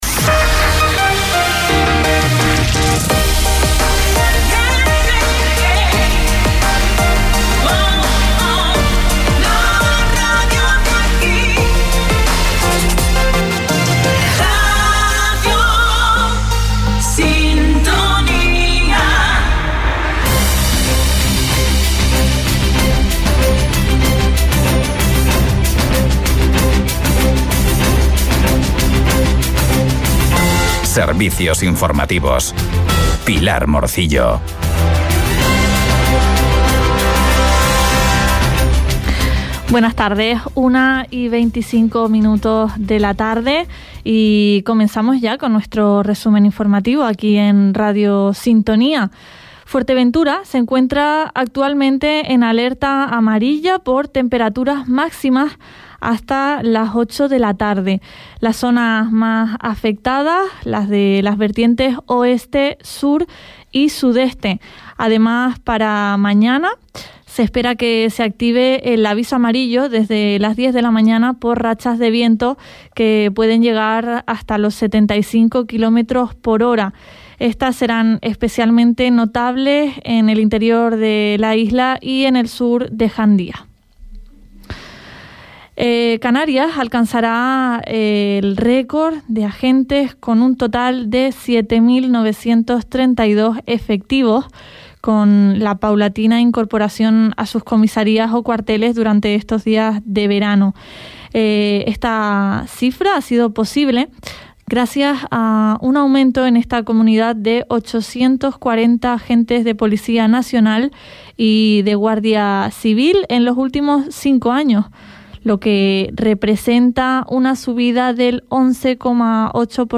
Informativos en Radio Sintonía - 29.06.23